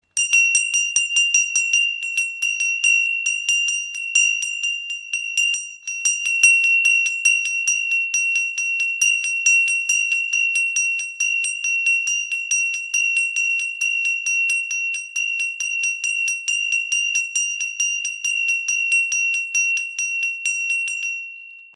Tempelglocke mit Shivafigur | Symbol für Transformation und Erneuerung · ø 9 cm
Diese Tempelglocke aus Rishikesh mit einer Shivafigur und einem mythologischen Pferdeaufhängehaken symbolisiert Transformation und spirituelles Wachstum. Ihr klarer Klang ist ideal für Meditationen und Rituale.
Ihr klang ist tief, klar und durchdringend – ideal, um den Raum zu reinigen, das Bewusstsein zu erweitern und sich mit dem Göttlichen zu verbinden.
• Material: Massives Messing